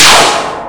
BossAttack.wav